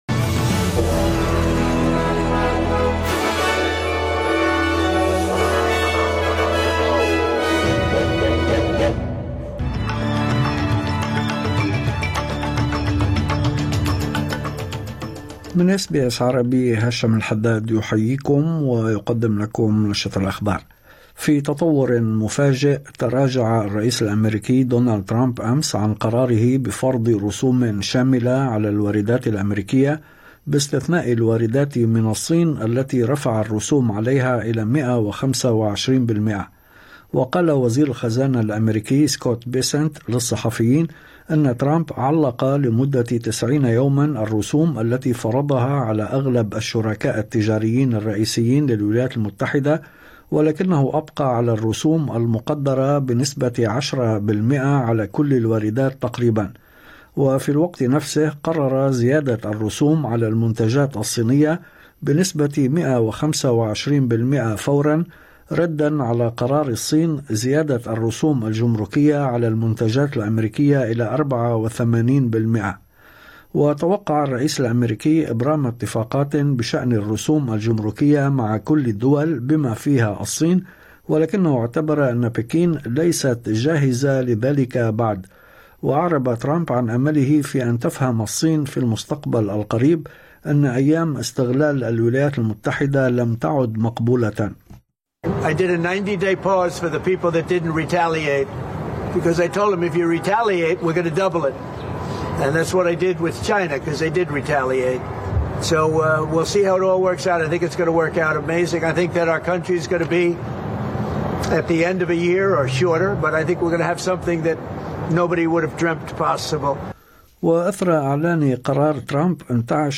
نشرة أخبار الظهيرة 10/04/2025